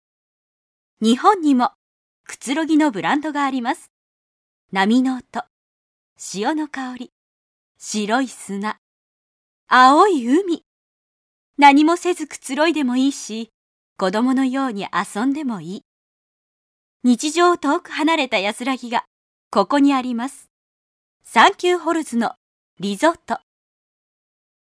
日本語 女性